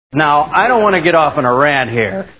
Dennis Miller Live TV Show Sound Bites